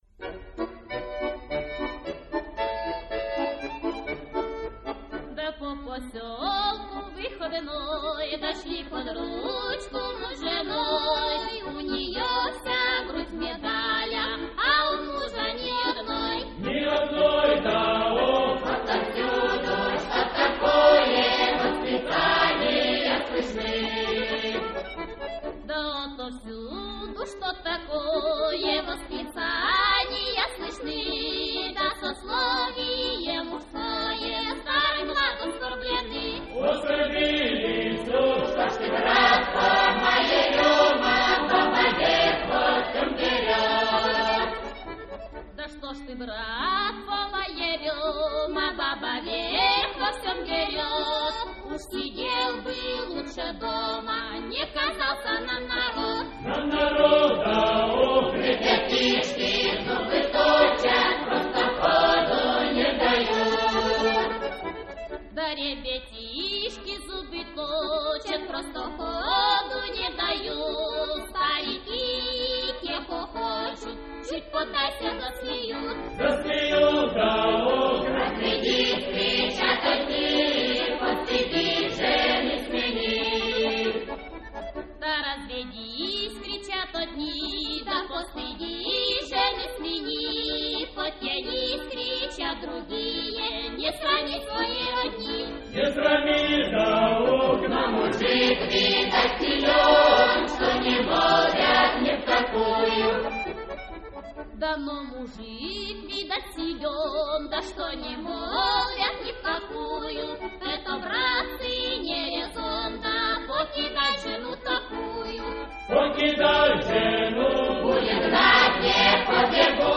Запись 1955-1959. Оцифрована на советской аппаратуре.